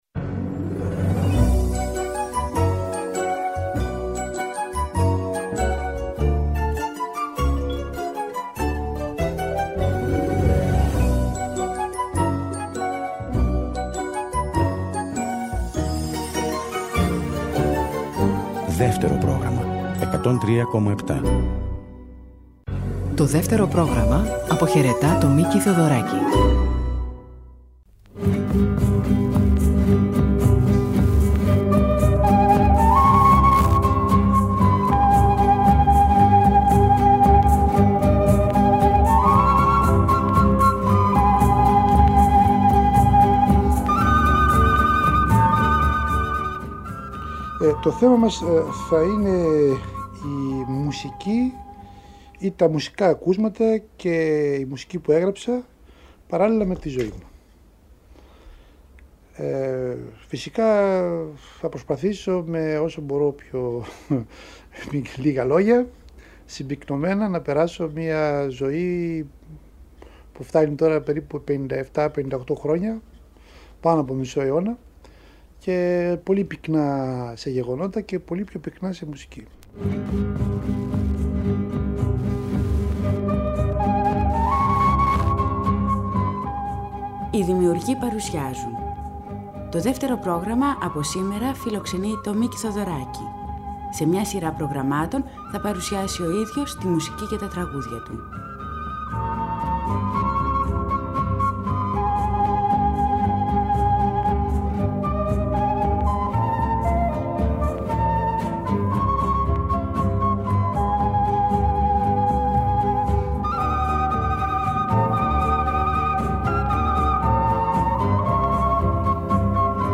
Εδώ, ο Μίκης Θεοδωράκης, μόνος μπροστά στο μικρόφωνο, ξετυλίγει με τον δικό του γλαφυρό τρόπο το κουβάρι των αναμνήσεών του και αφηγείται τη ζωή του.